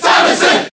File usage The following 3 pages use this file: List of crowd cheers (SSBB)/Japanese Samus (SSBB) File:Samus Cheer JP Brawl.ogg Transcode status Update transcode status No transcoding required.
Samus_Cheer_Japanese_SSBB.ogg